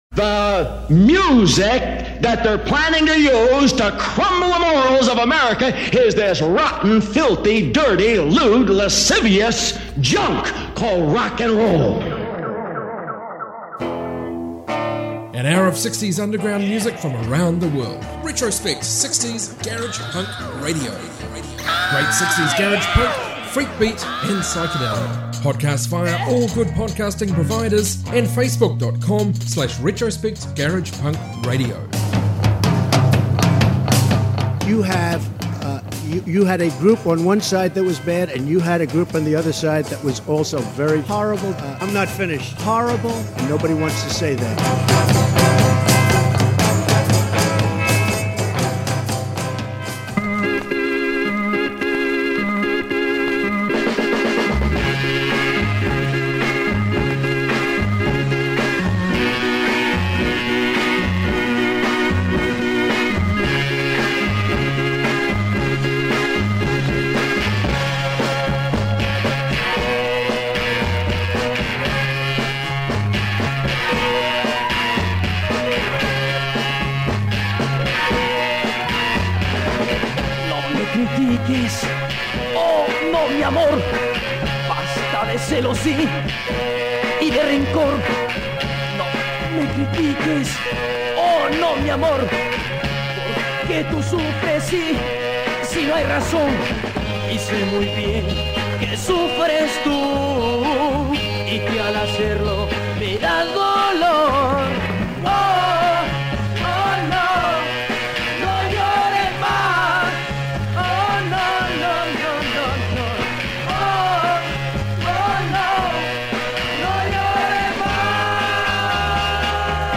60s garag emusic from around the world